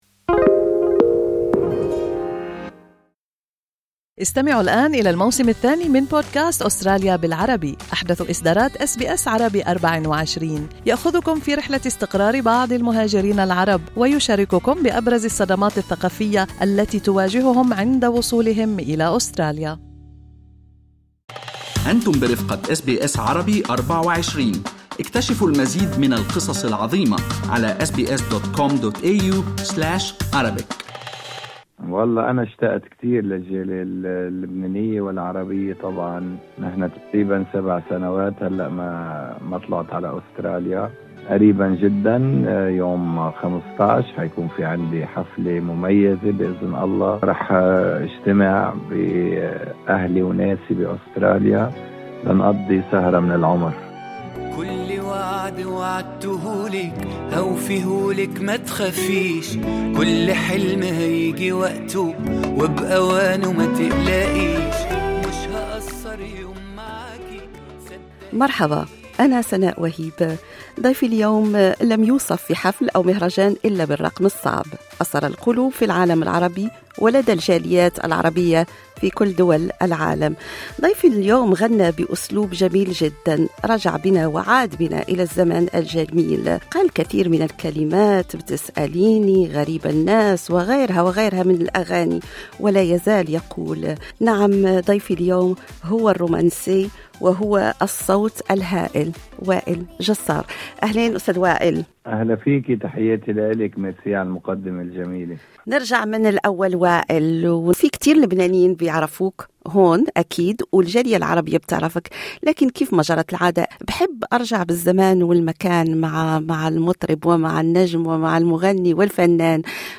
تحدث وائل مع أس بي أس عن محطاته الغنائية وعن رحلته الفنية وعن غياب دام 7 سنوات عن جمهوره في أستراليا.